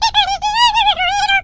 squeak_lead_vo_02.ogg